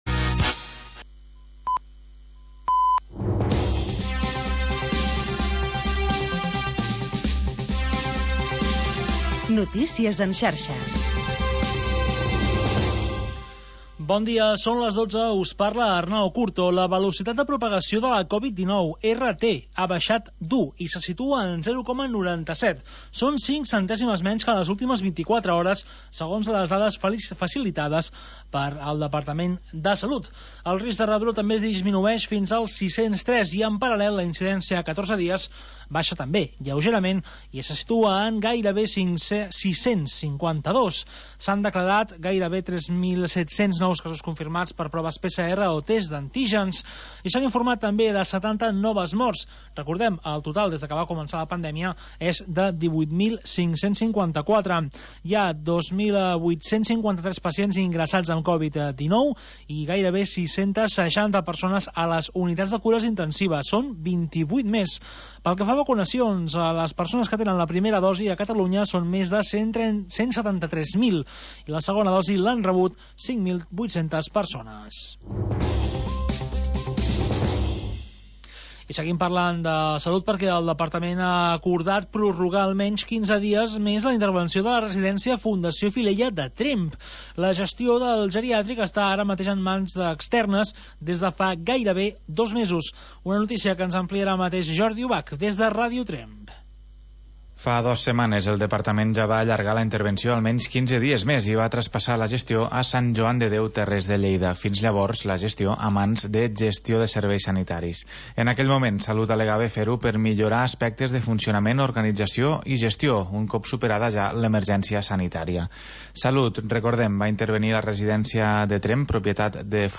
Programa sardanista